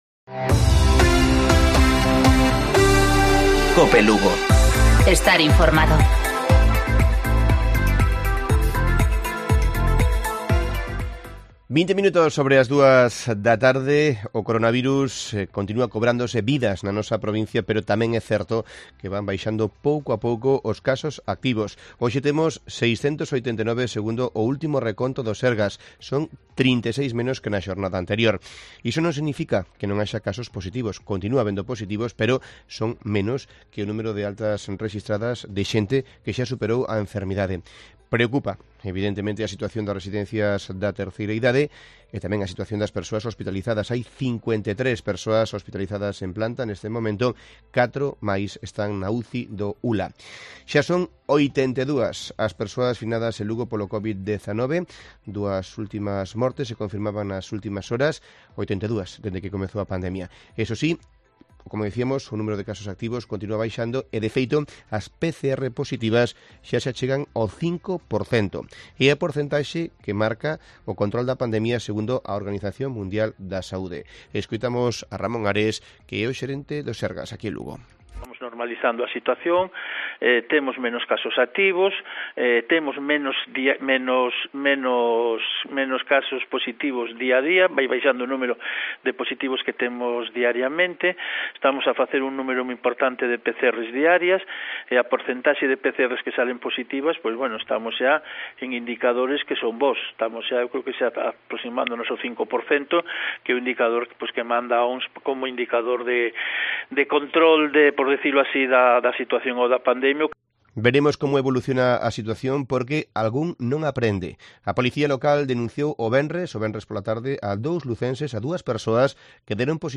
Informativo Mediodía de Cope Lugo. 21 de septiembre. 14:20 horas.